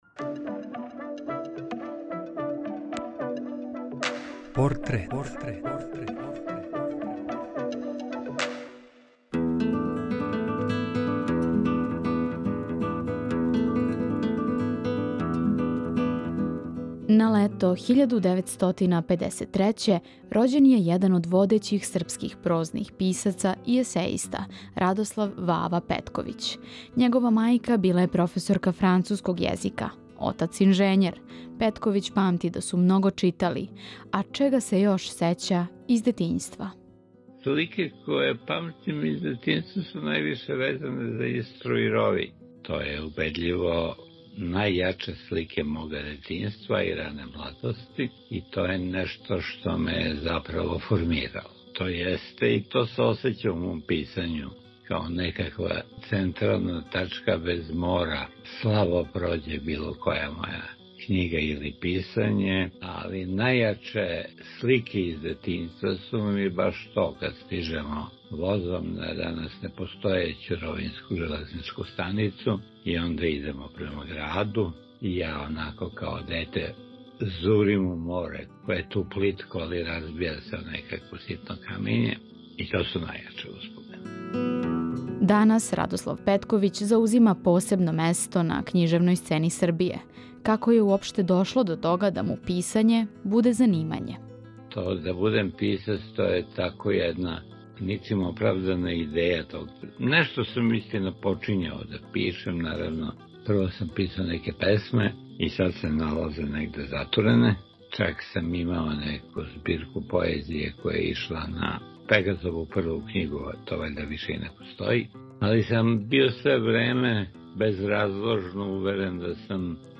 U emisiji „Portret“ poslušajte šta je Radoslav Petković rekao o svom detinjstvu, životnom putu, karijeri, nagradama i najdražim delima.